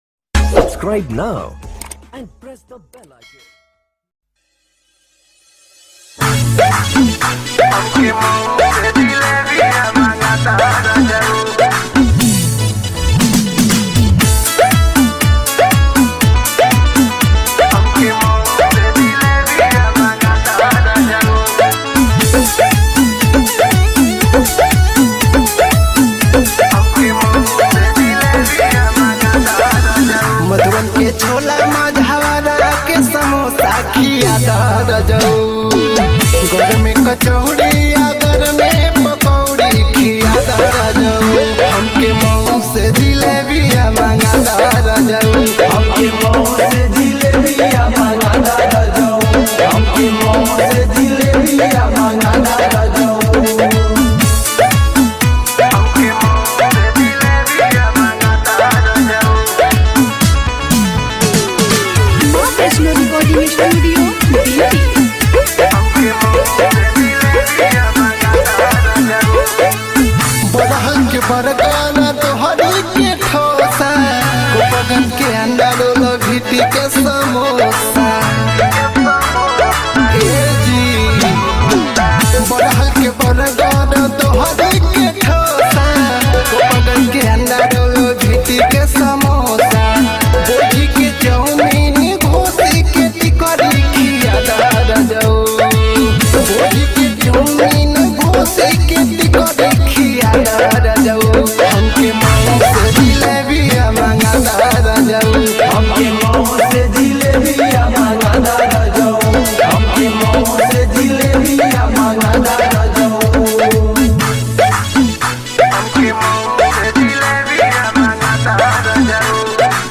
DJ Remix Song